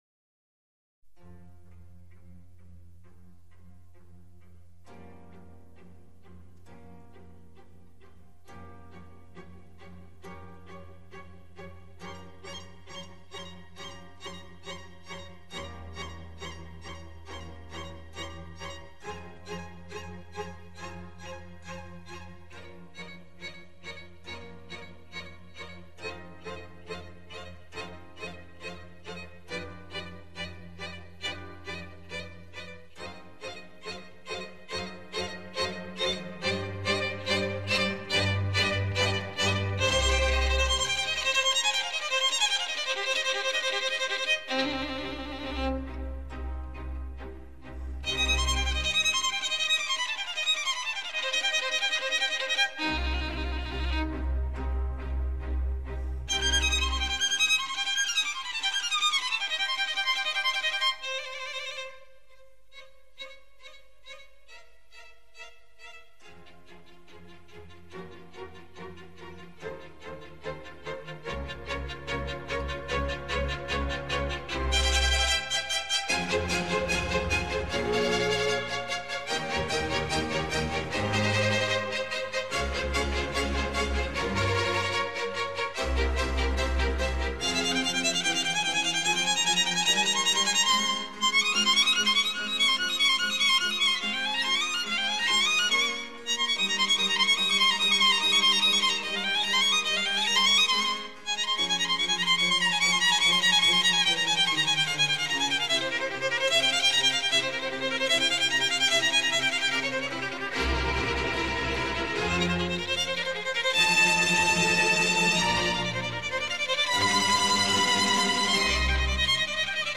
Música: Antonio Vivaldi - Winter (Full) - The Four Seasons (192) - Desconhecido No próximo domingo, 2 de novembro, é dia de finados, esses